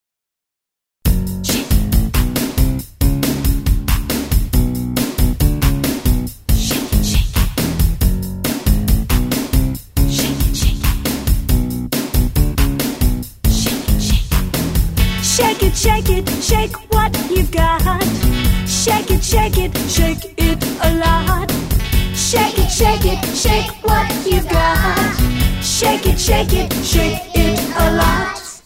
Action Song Lyrics and Sound Clip